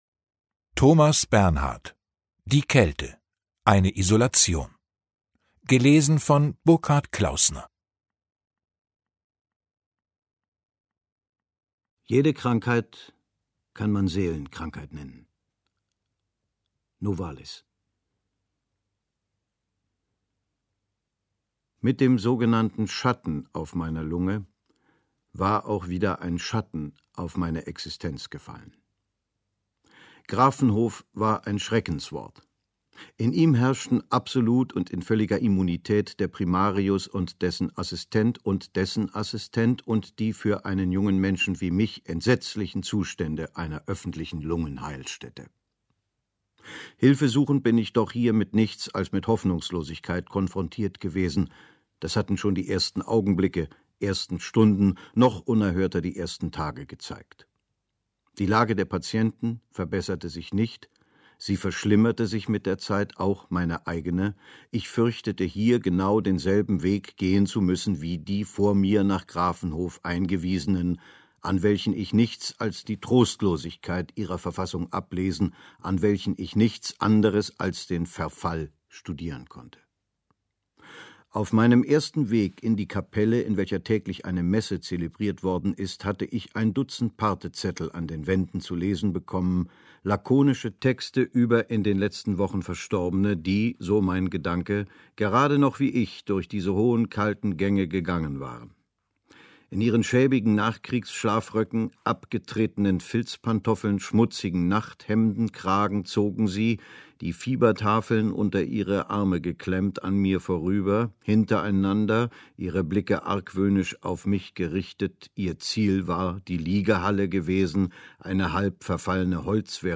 Die Kälte. Eine Isolation Ungekürzte Lesung mit Burghart Klaußner
Burghart Klaußner (Sprecher)